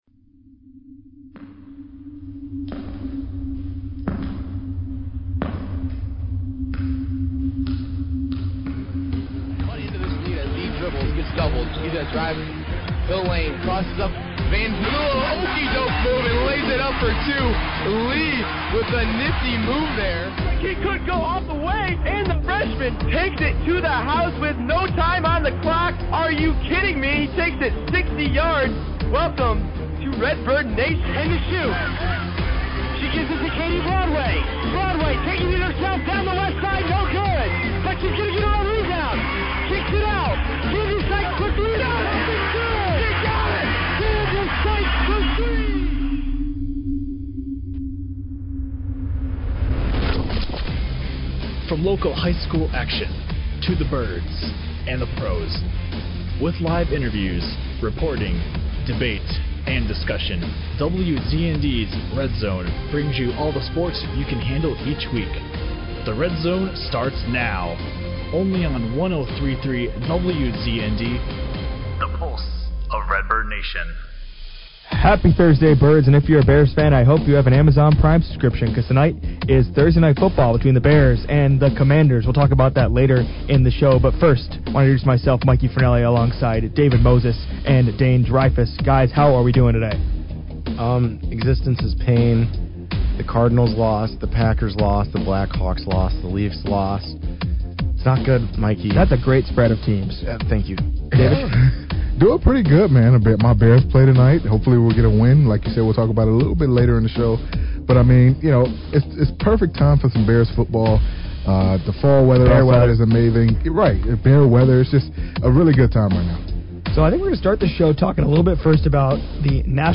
Prior to primetime Bears kickoff, the RedZone crew got together on another Thursday night to discuss all things local and national sports. They open the show with puck drop on the NHL season, going over expectations for a rebuilding Blackhawks team and making Stanley Cup picks.